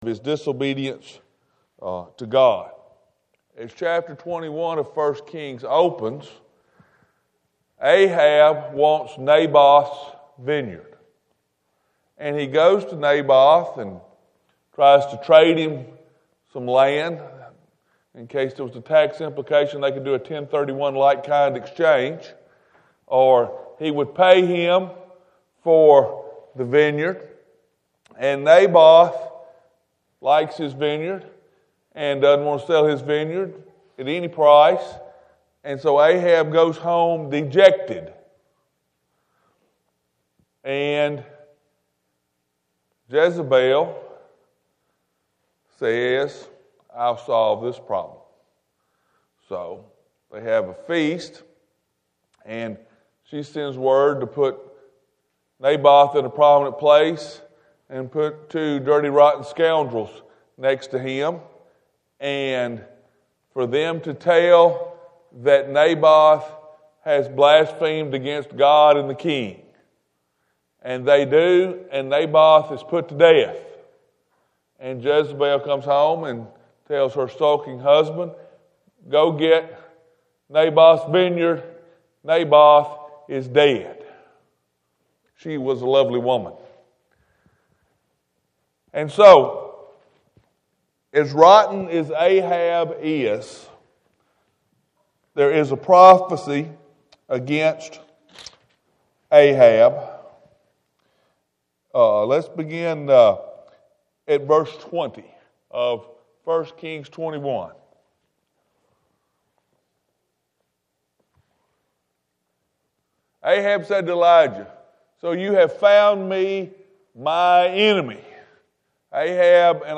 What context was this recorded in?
This is a partial recording because the microphone batteries died.